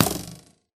bowhit2